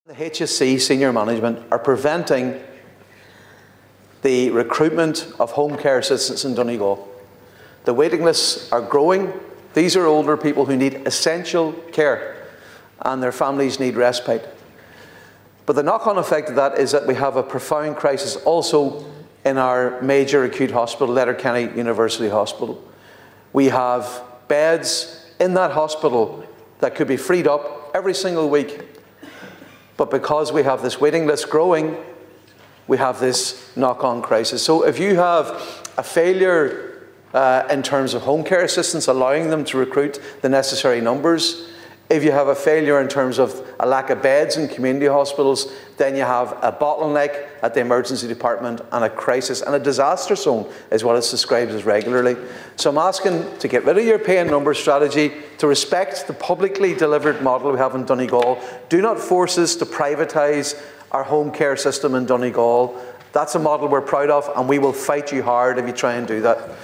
The issue is said to centre on a lack of home care assistance available in Donegal, with further claims that recruitment in the county is being prevented. Speaking in the Dail, Sinn Fein TD Padraig MacLochlainn says that's leading to a wi…